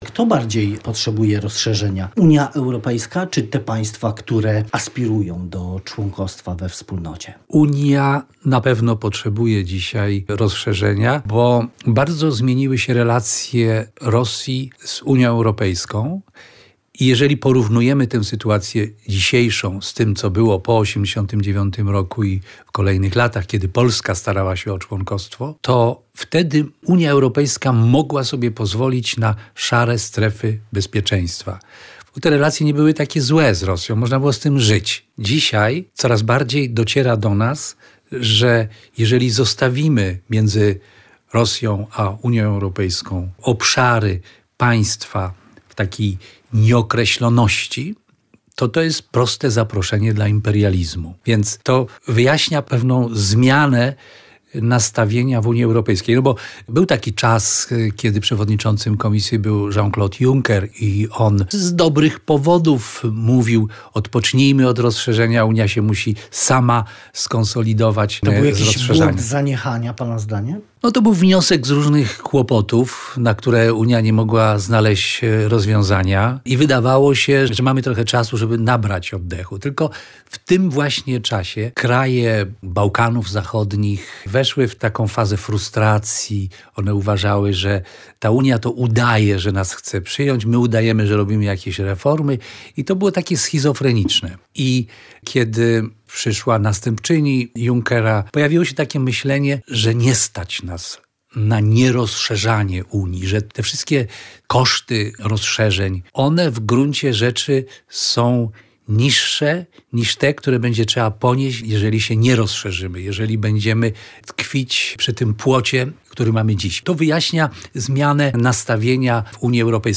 Rozmowa z wiceministrem spraw zagranicznych Markiem Prawdą